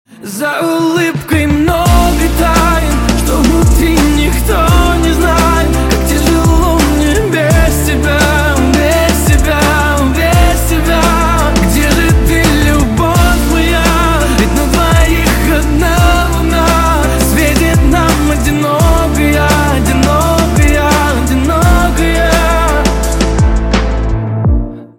романтические
лирика , поп
красивый мужской голос , медленные